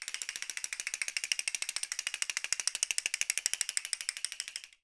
10 TOY CAS.wav